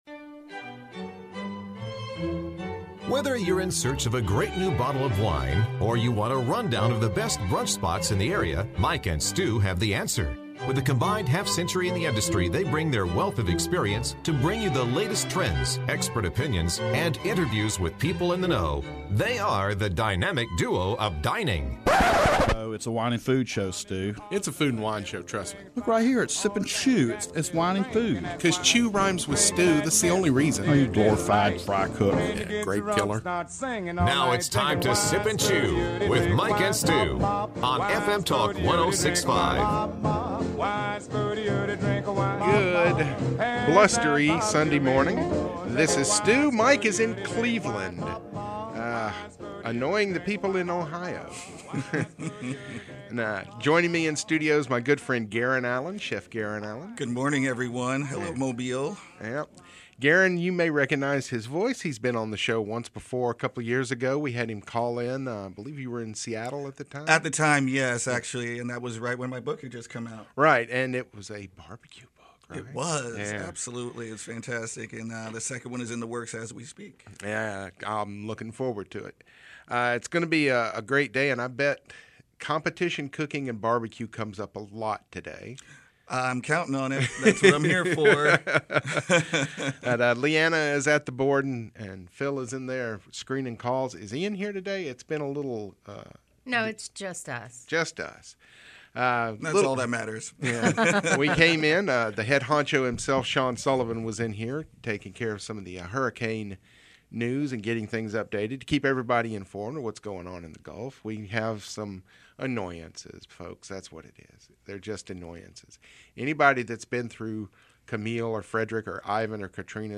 weekly foodie show